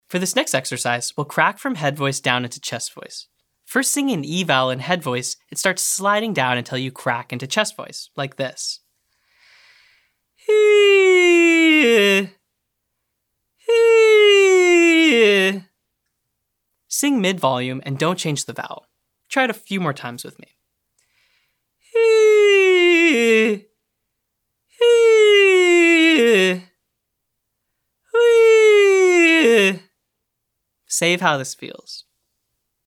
Intentional Cracking